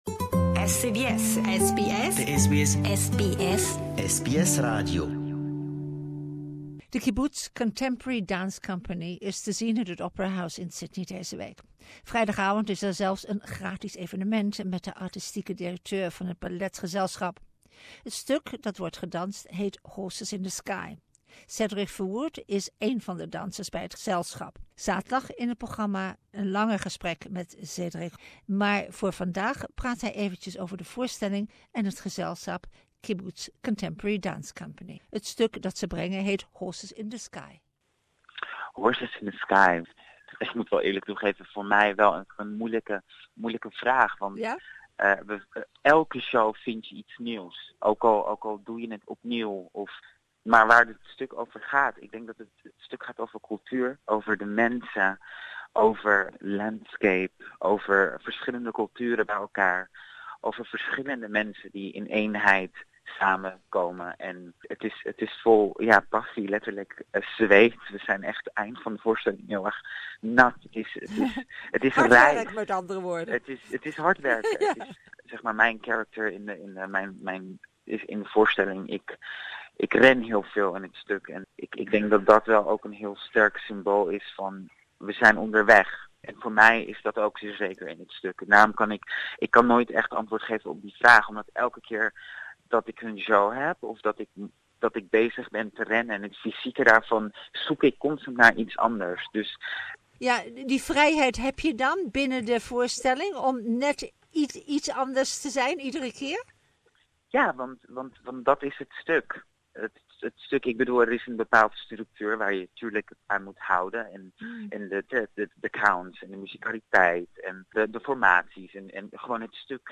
WEBSITE exclusive interview